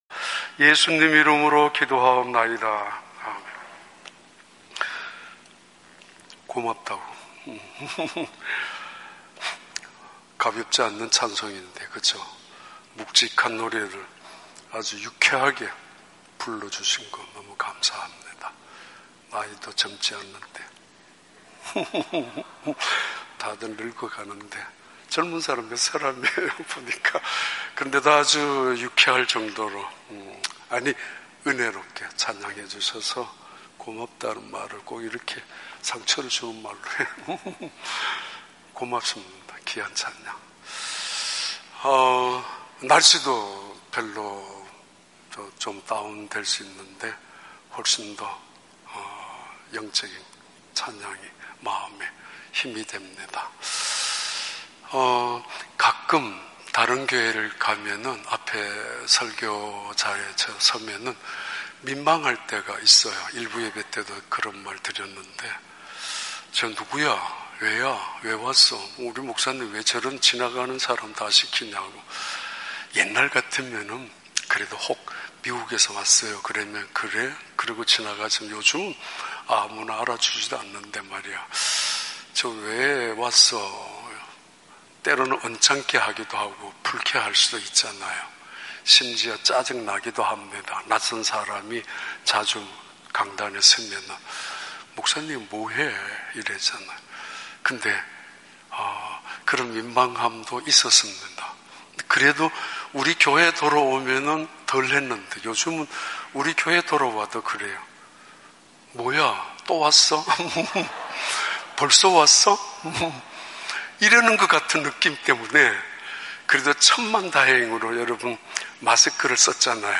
2022년 8월 21일 주일 3부 예배